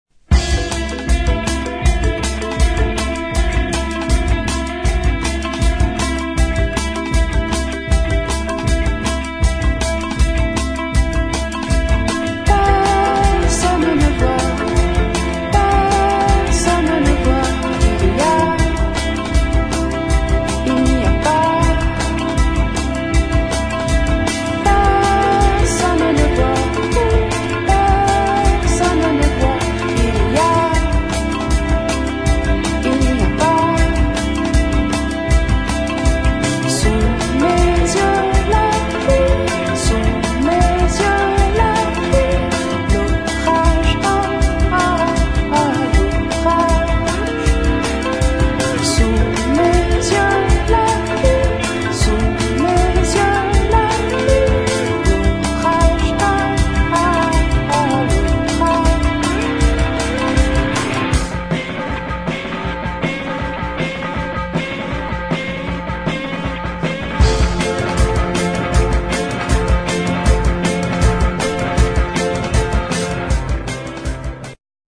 [ JAZZ | ROCK | FUNK | WORLD ]